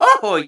Super Mario Ohhhoo